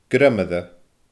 greimire /grʲemɪrʲə/